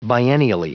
Prononciation du mot biennially en anglais (fichier audio)
Prononciation du mot : biennially